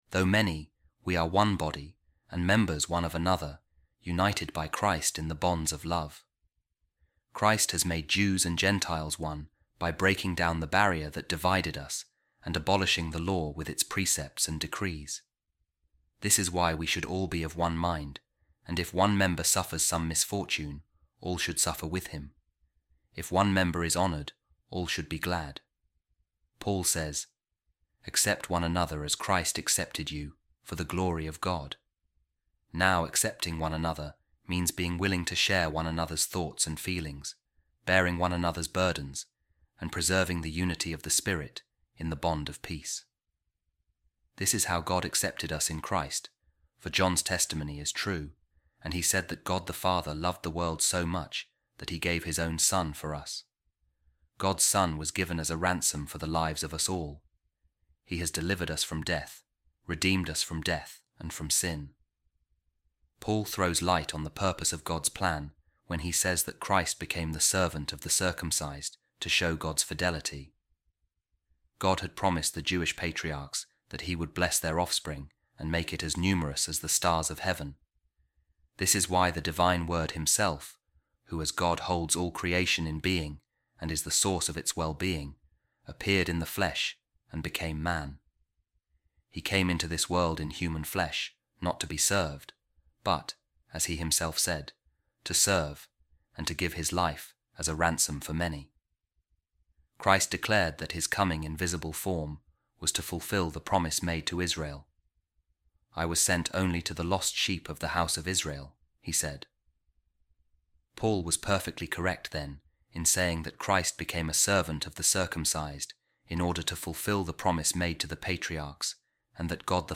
Office Of Readings | Eastertide Week 4, Saturday | A Reading From The Commentary Of Saint Cyril Of Alexandria On The Letter To The Romans | All Are Saved Through Jesus | Opus Dei | Body Of Jesus Christ – Word Aloud | Daily Bible Verses | Audio KJV
office-readings-easter-saturday-4-saint-cyril-alexandria-opus-dei.mp3